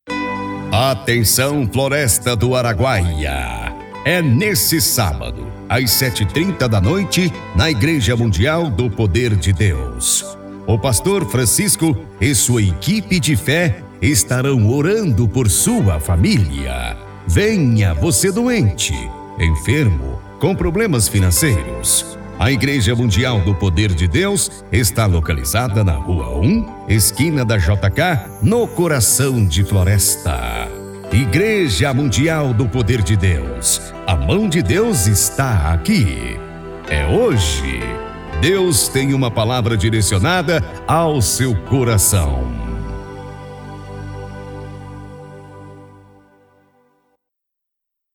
DEMO LOCUÇÃO IMPACTO IGREJAS: